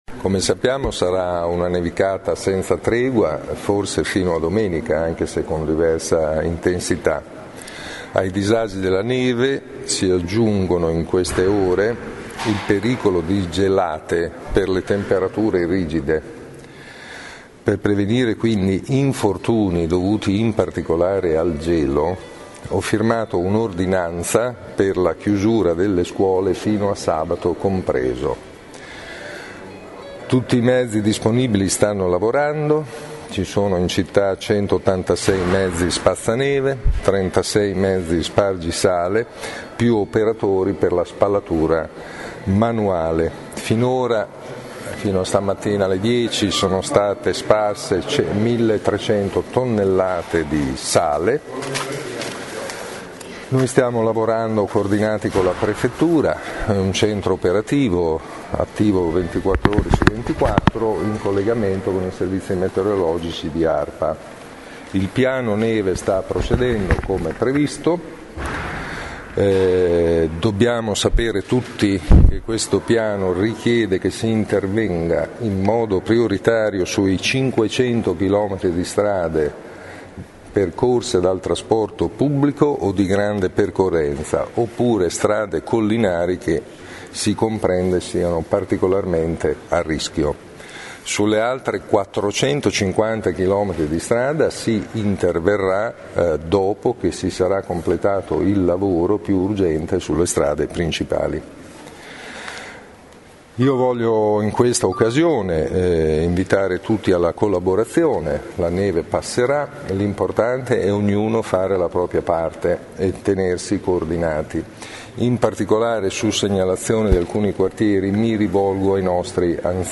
Ascolta il sindaco: